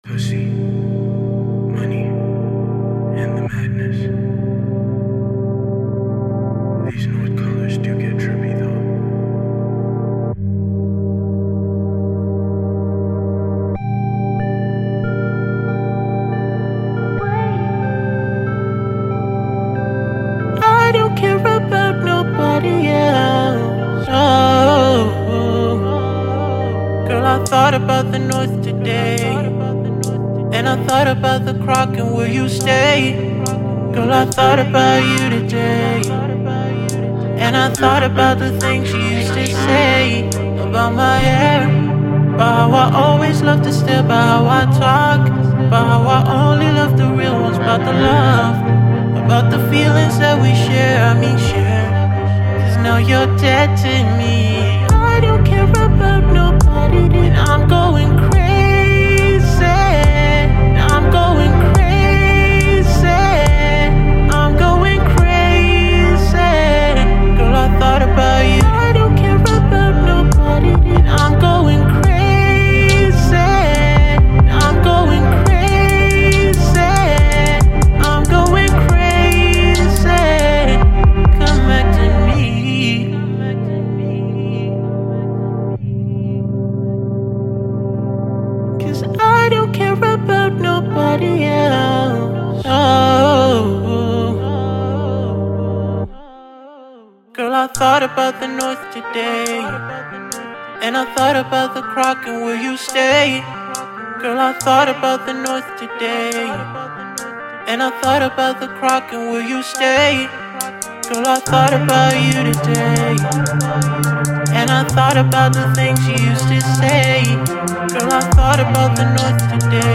Trippy Blues Singer
smooth Ballad